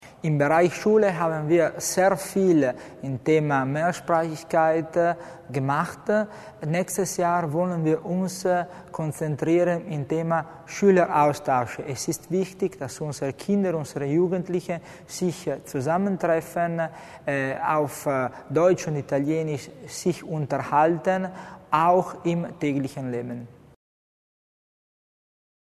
Die Landesregierung hat im kommenden Jahr die Änderung des Wohnbaugesetzes, die Fertigstellung eines Bausparmodells und die Zusammenlegung des Wohn- und Mietgeldes im Visier. Dies hat Wohnbau-Landesrat Christian Tommasini heute (18. November) bei der Vorstellung seiner Haushaltsschwerpunkte angekündigt.